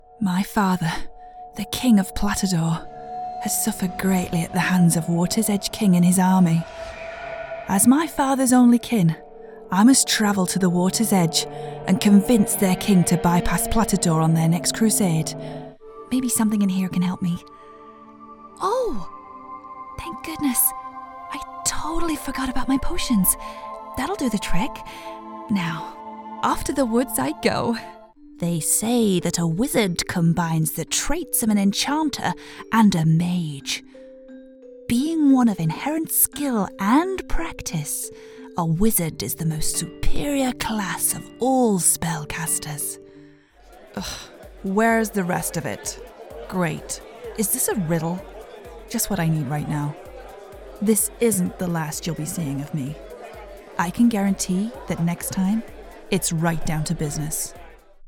A natural, relatable and versatile voice you can trust!
Sprechprobe: Sonstiges (Muttersprache):
She has a neutral British accent that is soothing, clear, friendly and most importantly, versatile.